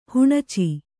♪ huṇaci